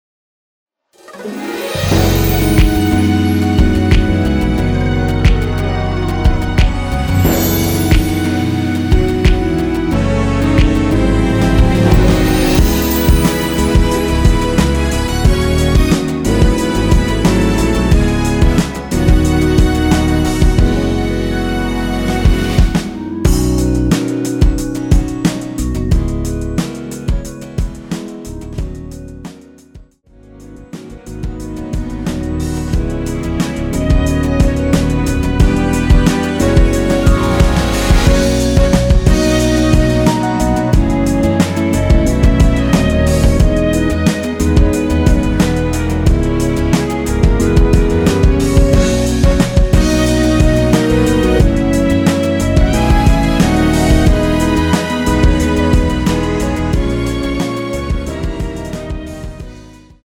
원키에서(-4)내린 (1절앞+후렴)으로 진행되는 멜로디 포함된 MR입니다.(미리듣기 확인)
멜로디 MR이란
앞부분30초, 뒷부분30초씩 편집해서 올려 드리고 있습니다.